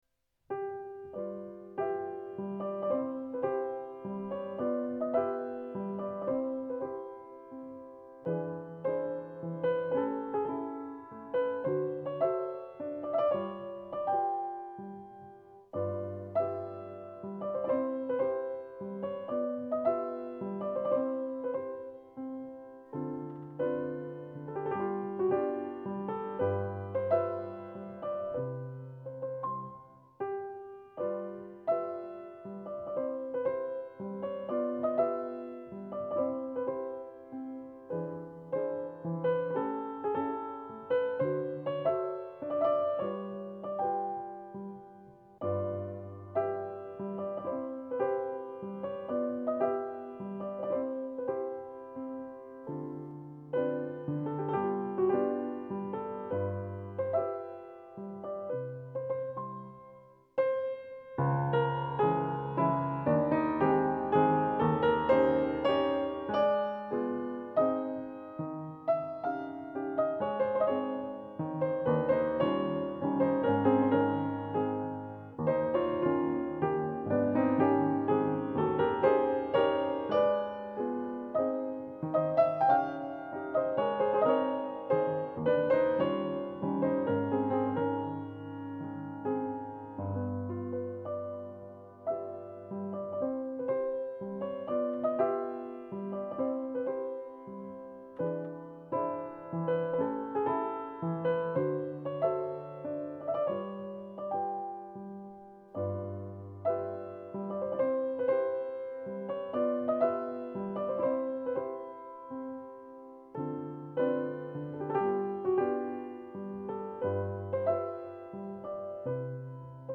Advanced Level: Recommended for Advanced Players
Piano  (View more Advanced Piano Music)
Classical (View more Classical Piano Music)